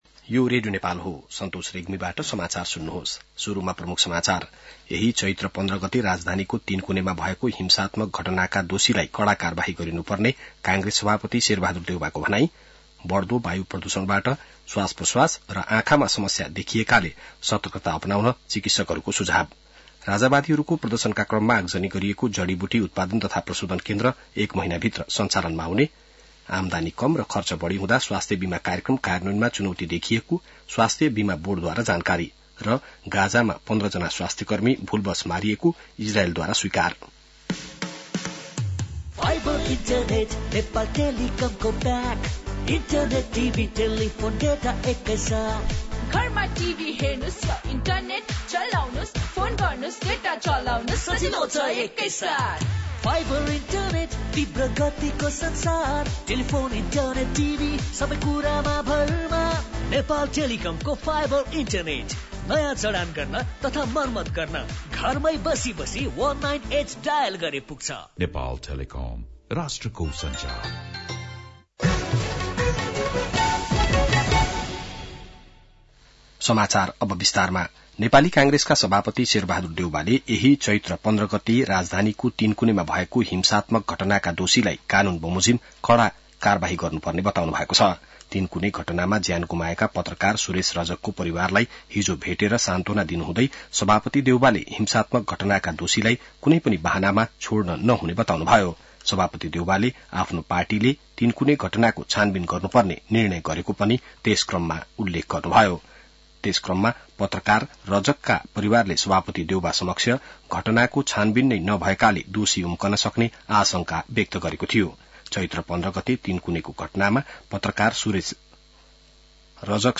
बिहान ७ बजेको नेपाली समाचार : २५ चैत , २०८१